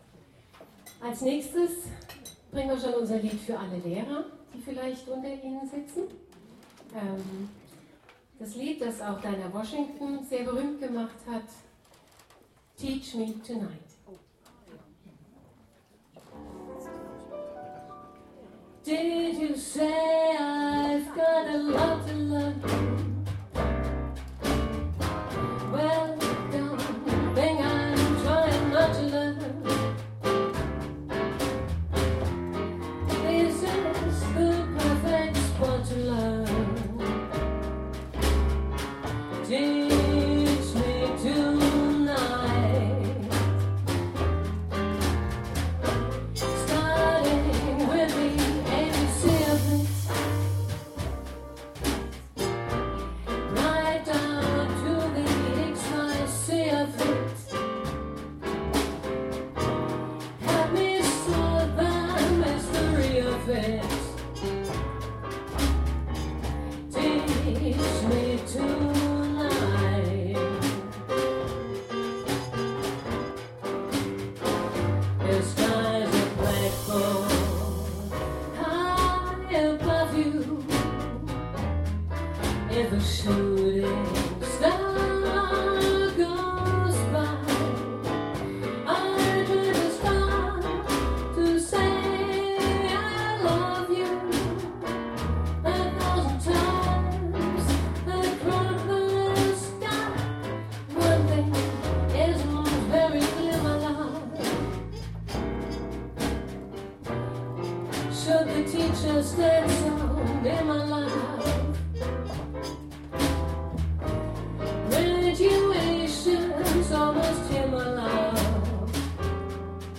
Shrink&Jazz in Vögi's Chäller
Ein Konzert in einer einmaligen Lokalität.
trumpet